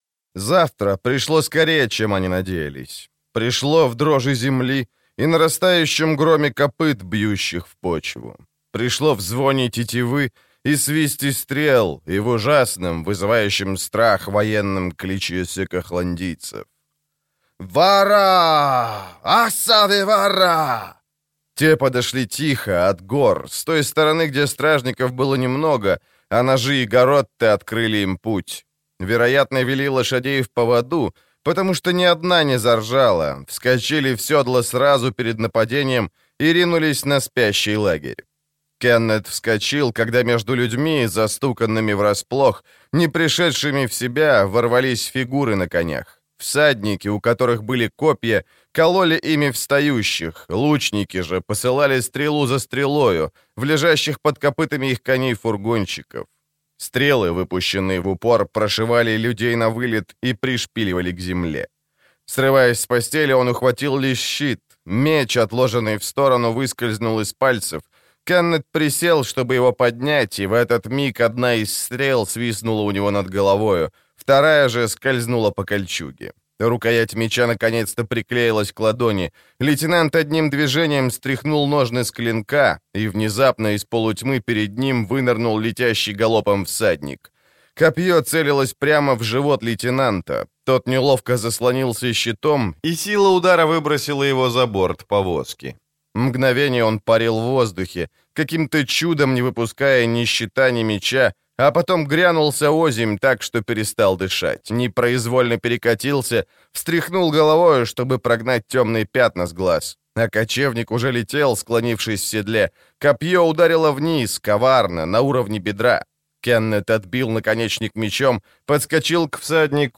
Аудиокнига Сказания Меекханского пограничья. Небо цвета стали | Библиотека аудиокниг